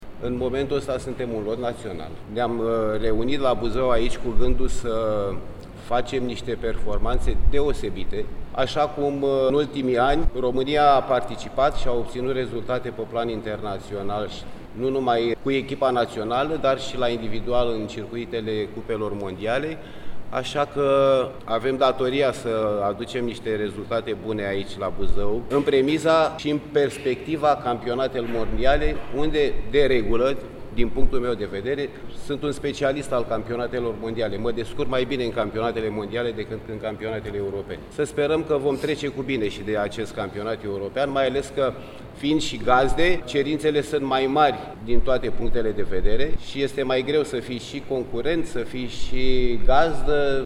În cadrul unei conferințe de presă organizată la Primăria Buzău